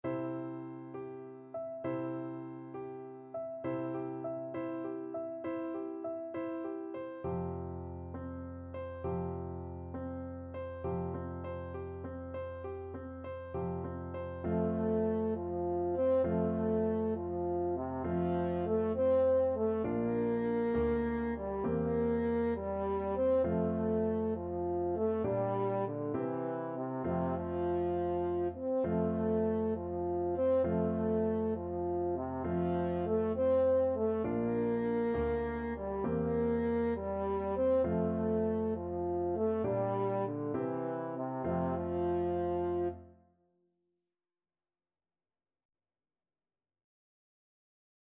Allegretto
C4-C5
Classical (View more Classical French Horn Music)